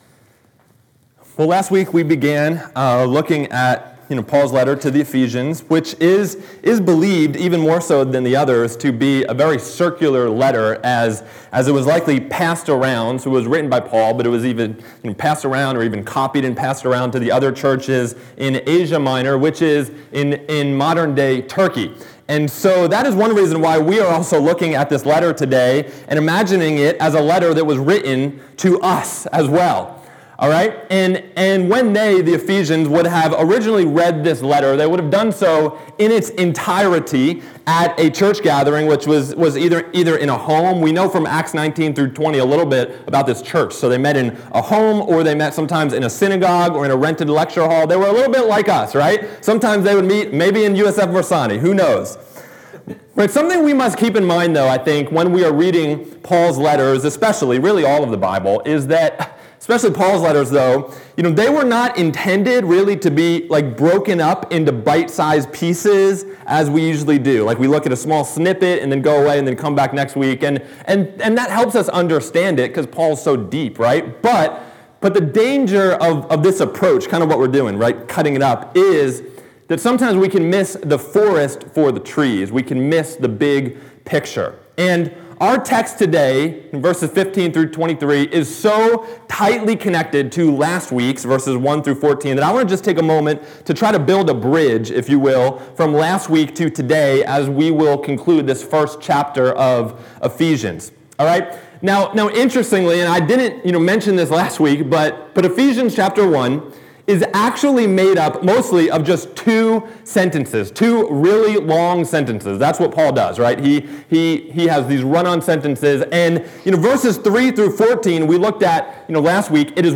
Knowing the Father Scripture Text: Ephesians 1:15-23 Date: May 4, 2025 AI Generated Summary: In this sermon, we explore how, as Christians adopted into God's family, we can truly know God as our Father through prayer rather than mere theological knowledge. Through this intimate relationship, we come to understand our past hope in God's calling, our future inheritance, and the immeasurable power available to us now—the same power that raised Christ.